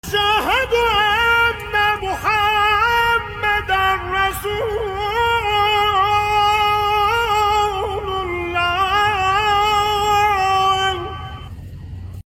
Recitation Quran